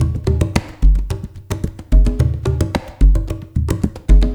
APH HANDRU-R.wav